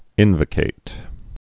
(ĭnvə-kāt)